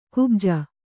♪ kubja